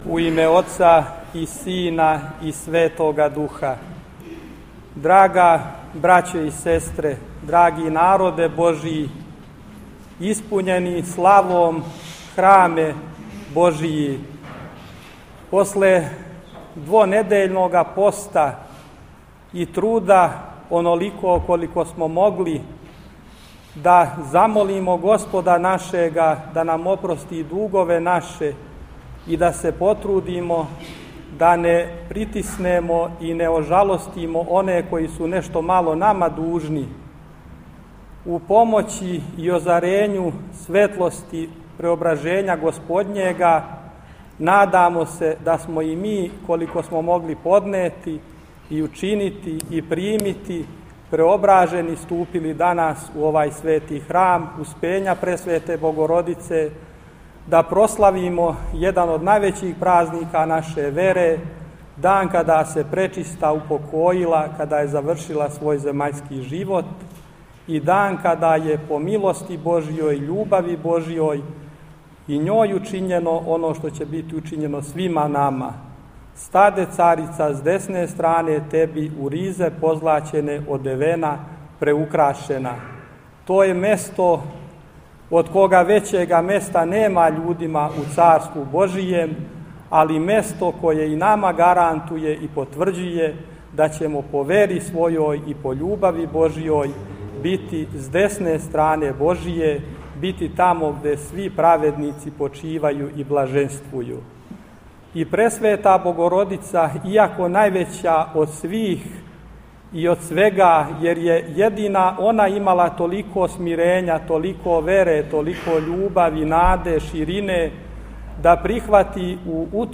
Град епископског седишта, Нови Сад, средишње славље имао је у у цркви посвећеној данашњем празнику, Успењу Пресвете Богородице.